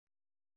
♪ kalvałi